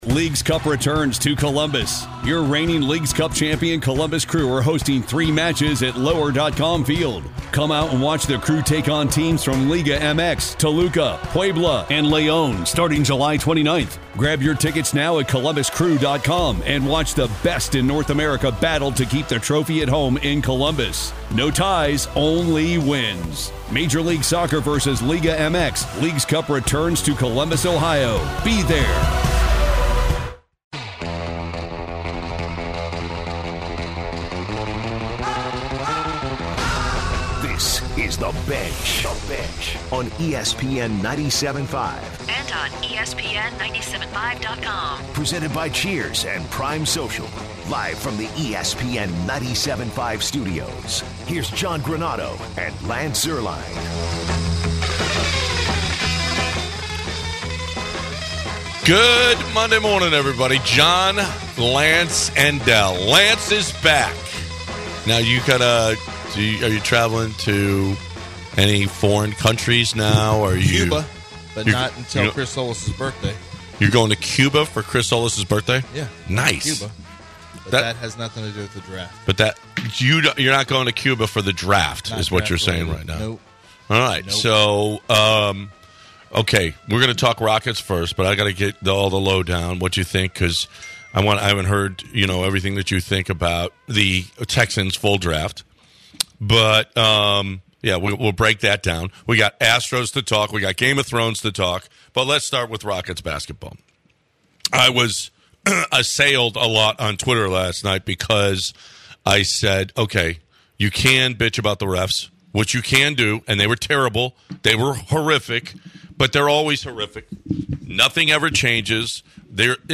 The guys get into Game of Thrones before wrapping the first hour with couple callers to talk about Rockets playoffs and the Texans Draft.